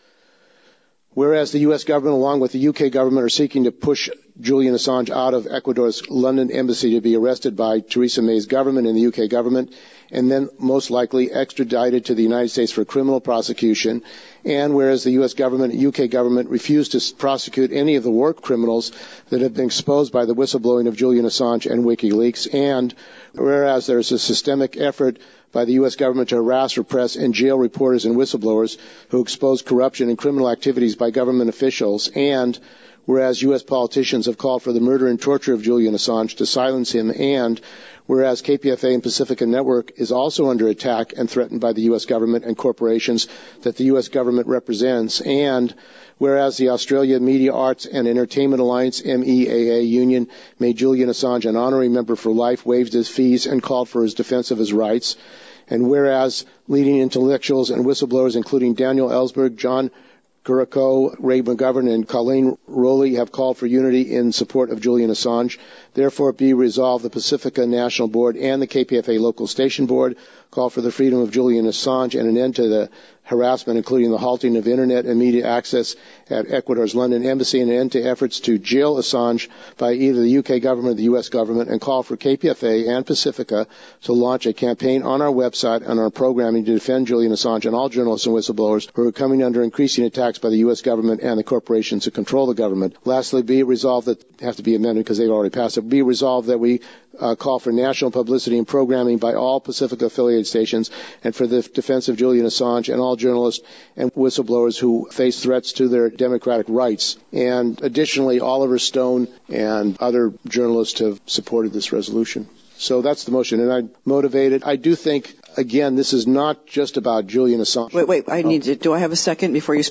This is the audio of that part of the board meeting.